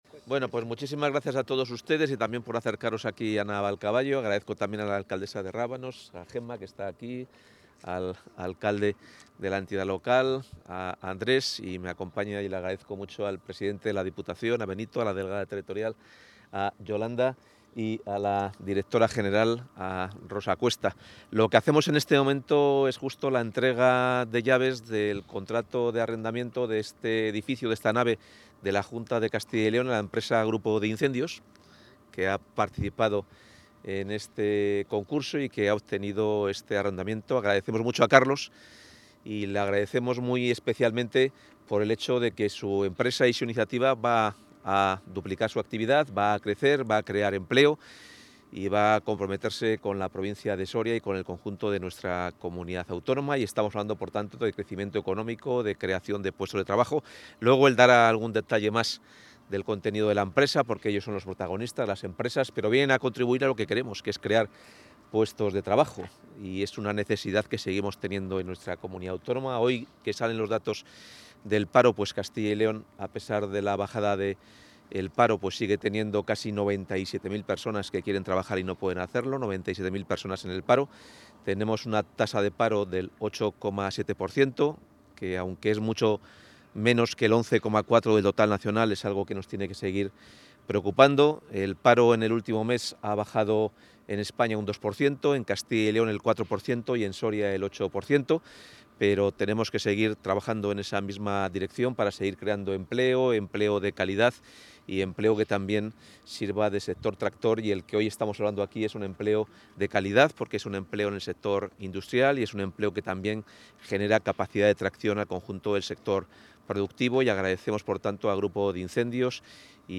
Intervención del consejero.